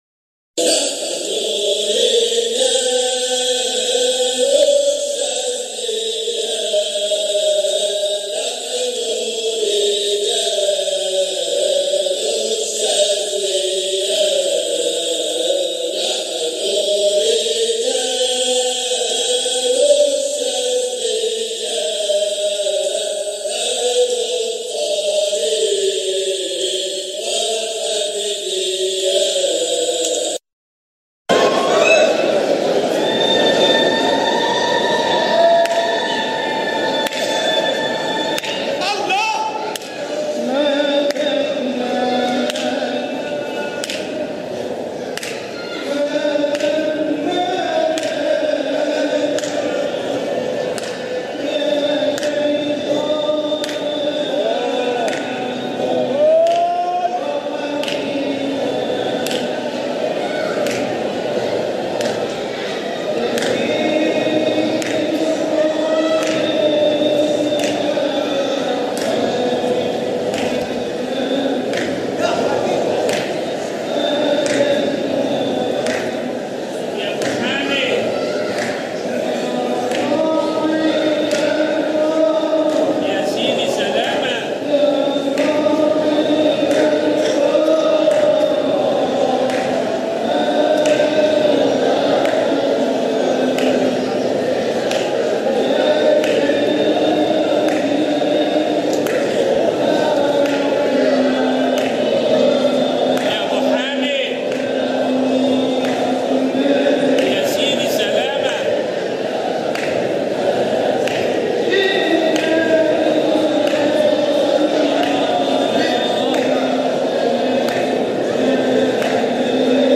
مقاطع من احتفالات ابناء الطريقة الحامدية الشاذلية بمناسباتهم
جزء من حلقة ذكر بمسجد سيدنا احمد البدوى قُدس سره 2017